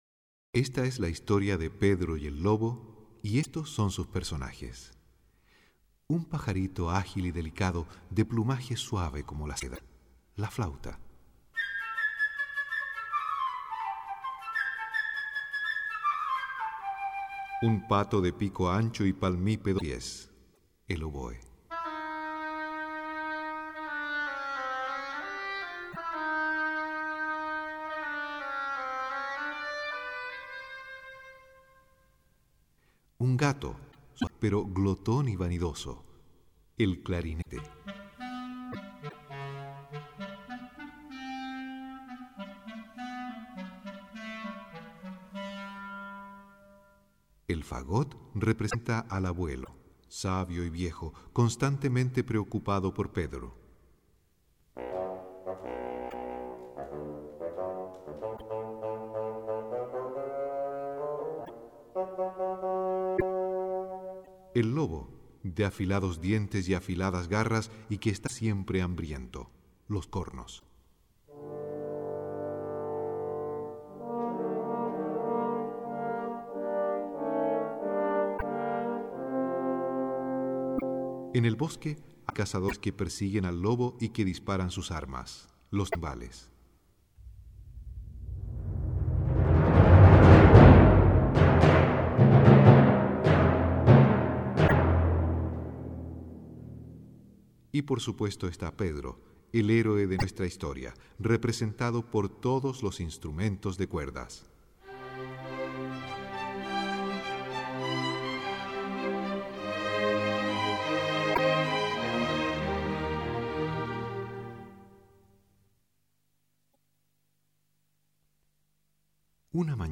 Audio con la narración del cuento "Pedrito y el Lobo".